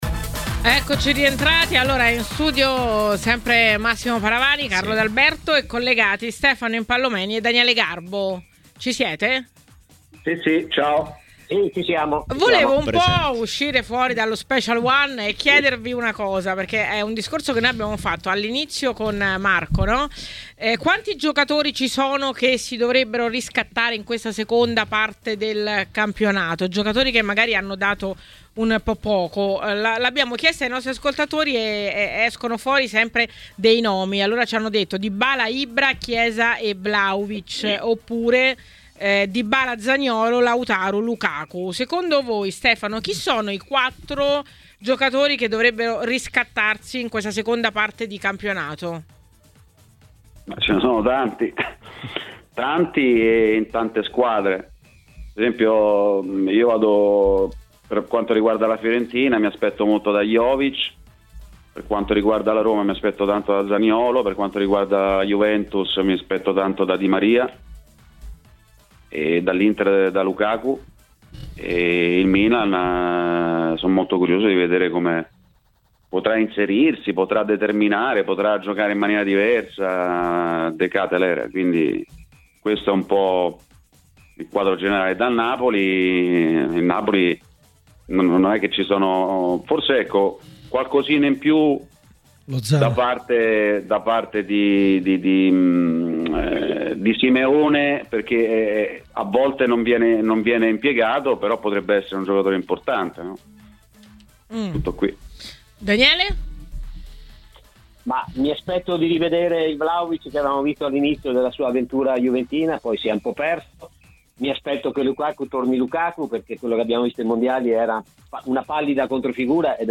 A commentare le notizie del giorno a Maracanà, nel pomeriggio di TMW Radio